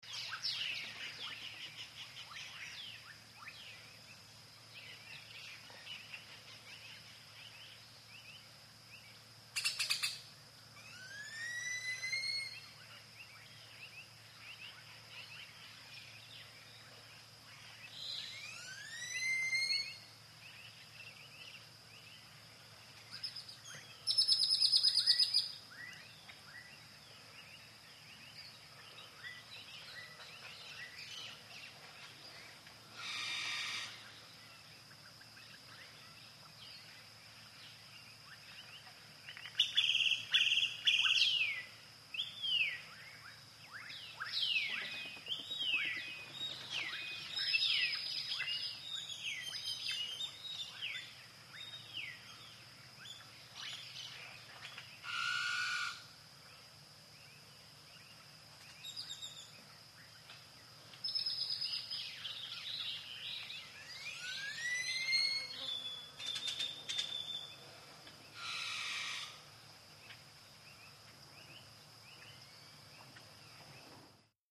Belize jungle, with Tanagers and Grackles ( Cayo district )